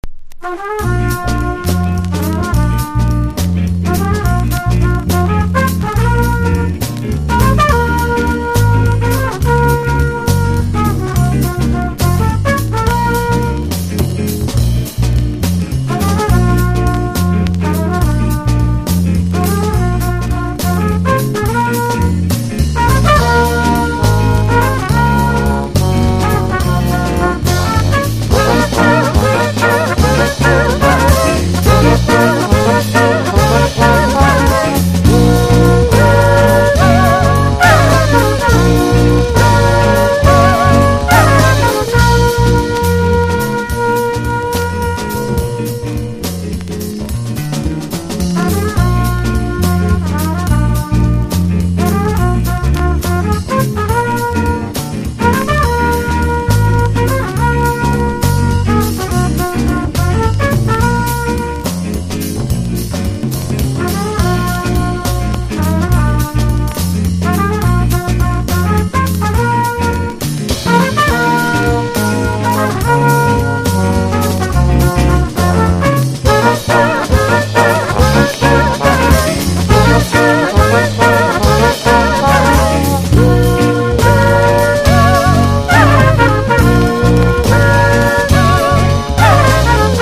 NEO ACOUSTIC / GUITAR POP
ブラジル経由のハッピー・ジャズ・フロム・ブリストル。